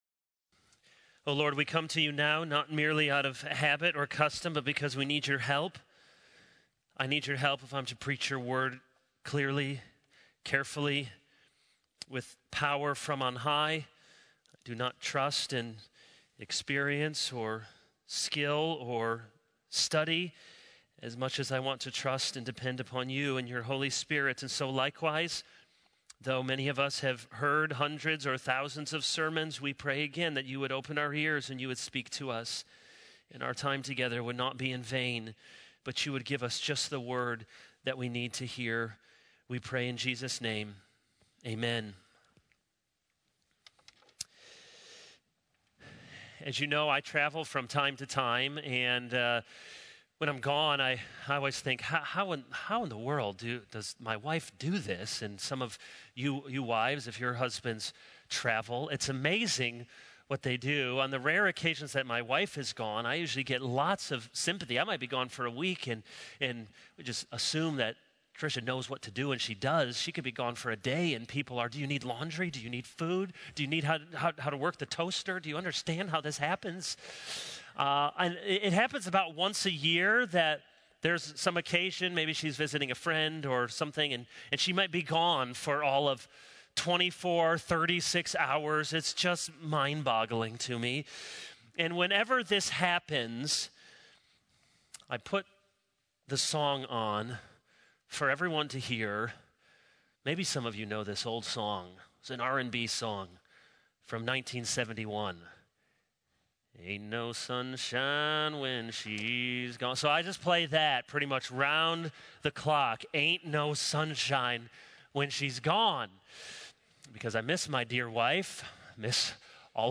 This is a sermon on Exodus 25.